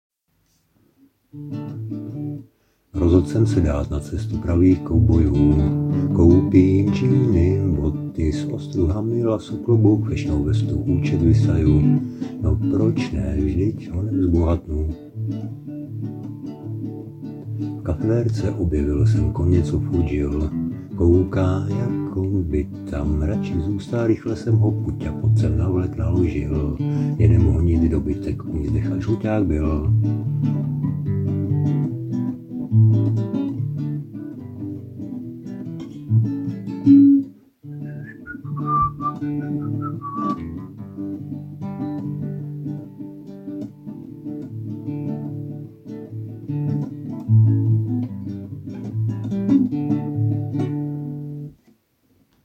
závidím ti, že umíš docela pískat